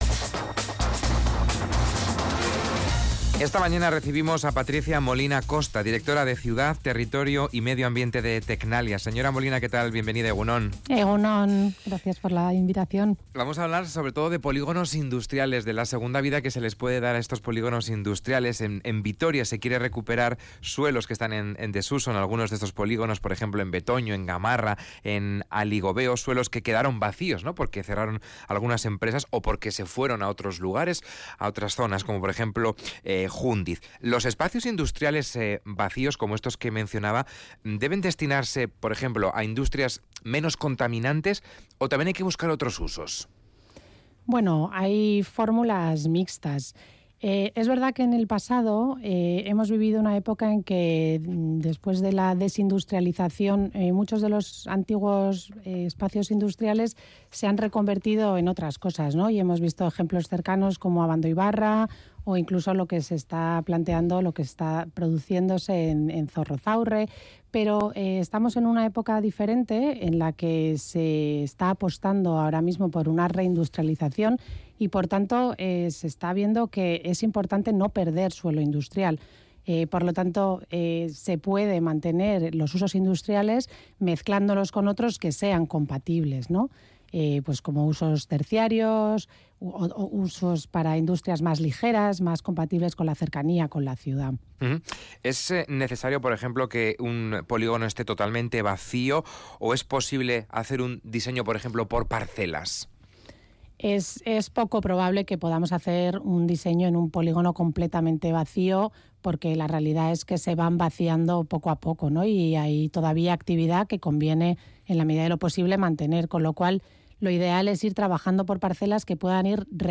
Radio Vitoria ENTREVISTA-DEL-DIA